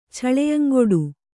♪ chaḷeyaŋgoḍu